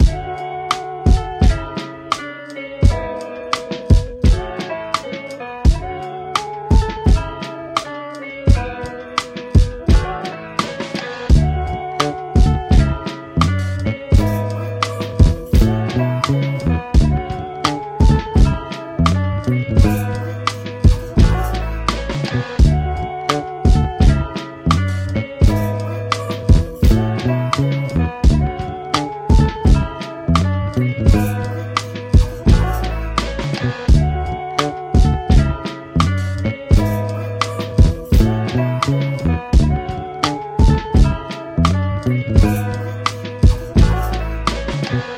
Hip Hop
E Major